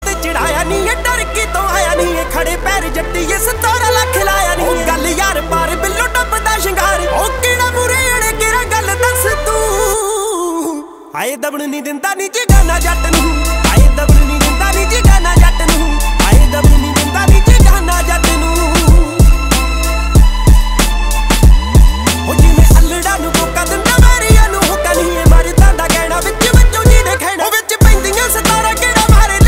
Punjab song